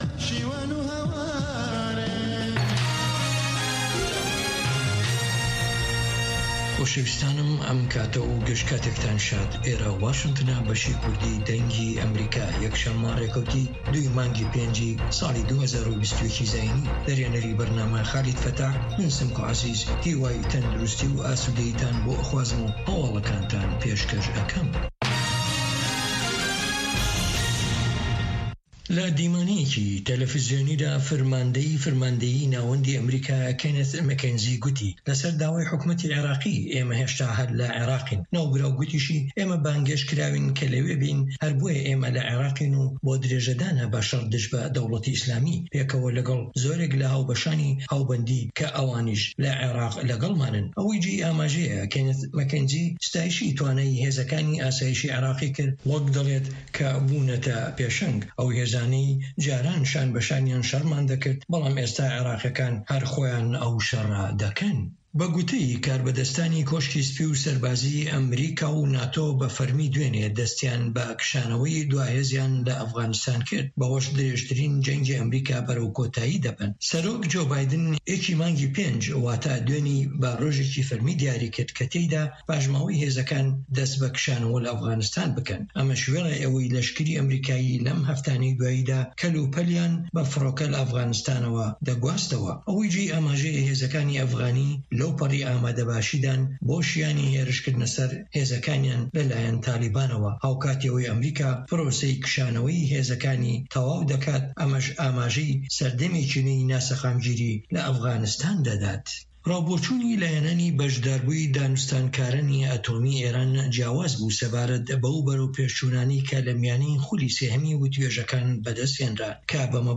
هه‌واڵه‌کان ، ڕاپـۆرت، وتووێژ، مێزگردی هه‌فته‌.